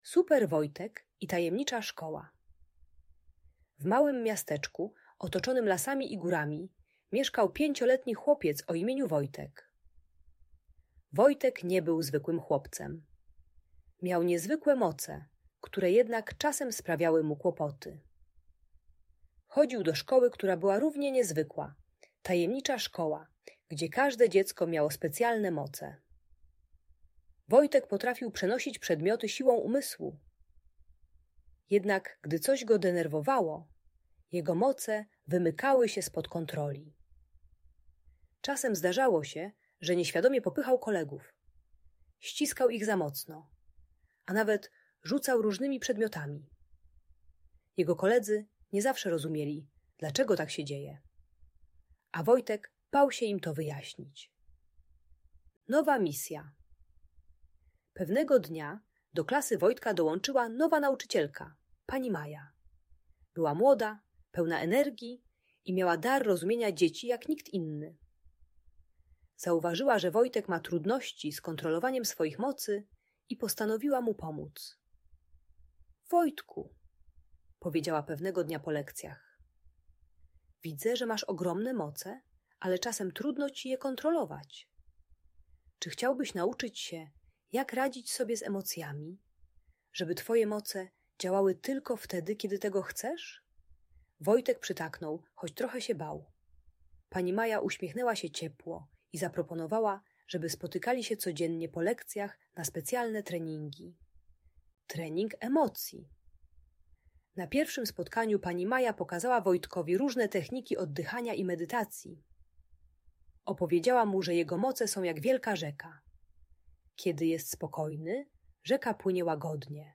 Historia o chłopcu, który uczy się kontrolować emocje poprzez technikę wizualizacji spokojnego jeziora i głębokiego oddychania. Audiobajka o agresji i radzeniu sobie ze złością dla dzieci które popychają lub biją kolegów.